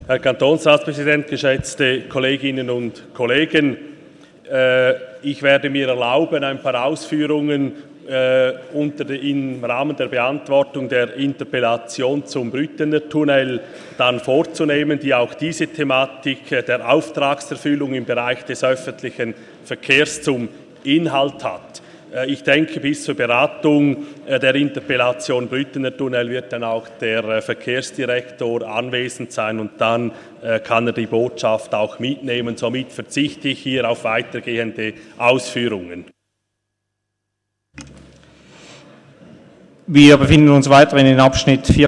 Session des Kantonsrates vom 12. und 13. Juni 2017